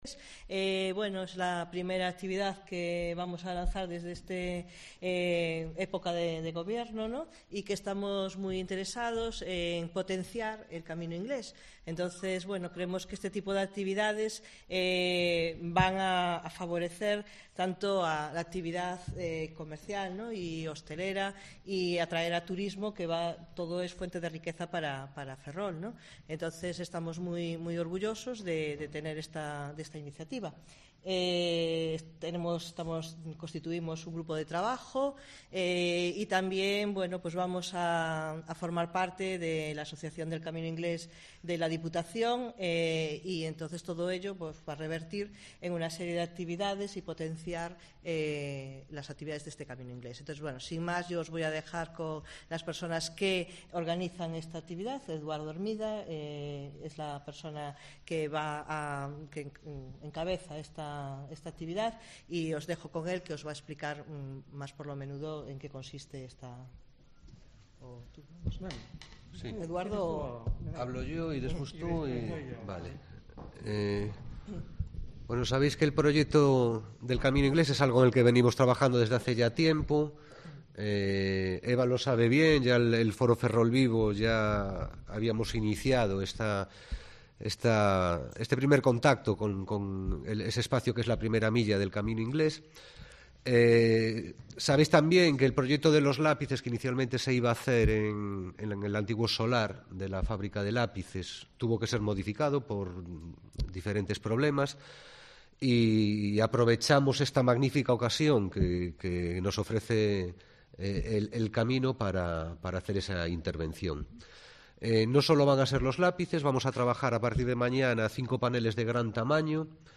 Informativo Mediodía Cope Ferrol 5/12/2019 (De 14.20 a 14.30 horas)